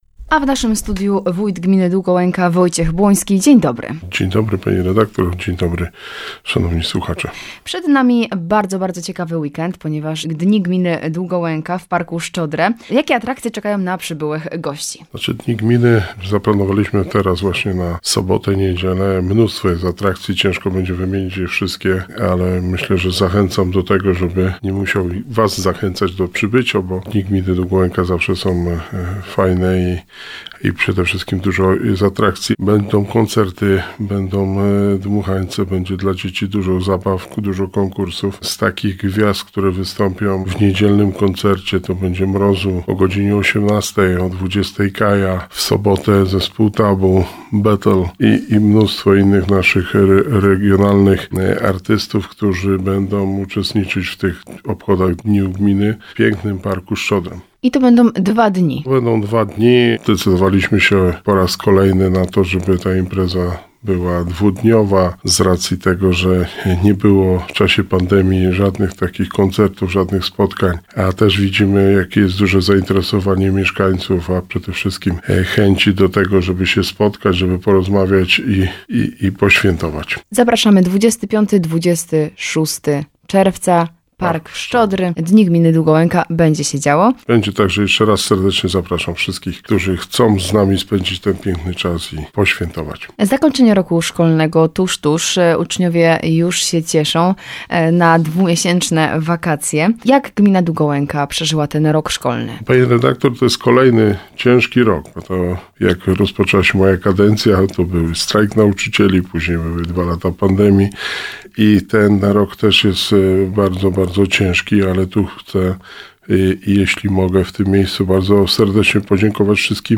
Rozmowa_Wojciech_Blonski_Radio_Rodzina_popr.mp3